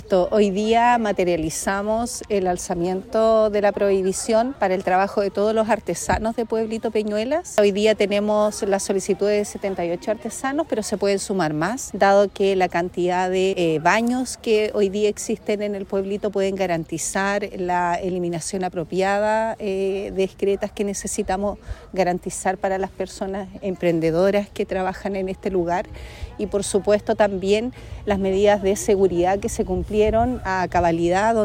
PAOLA-SALAS-SEREMI-DE-SALUD.mp3